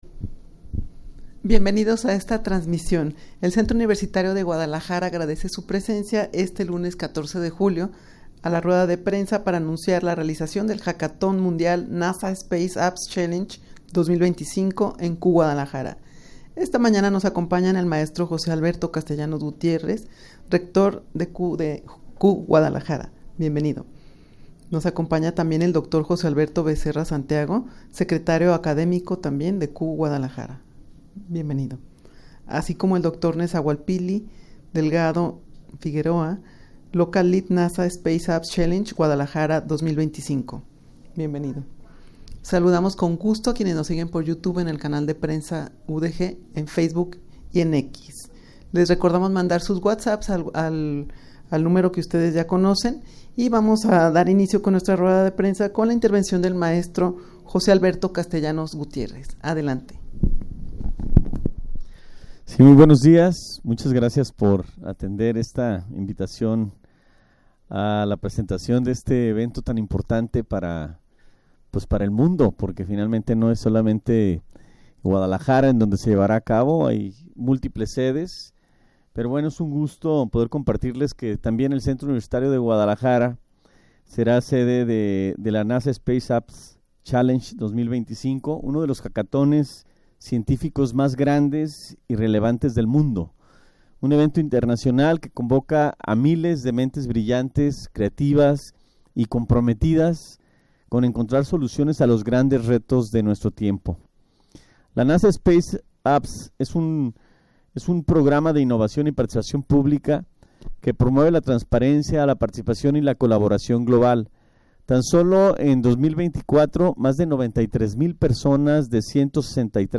Audio de la Rueda de Prensa
rueda-de-prensa-para-anunciar-la-realizacion-del-hackathon-mundial-nasa-space-apps-challenge-2025-en-cugdl.mp3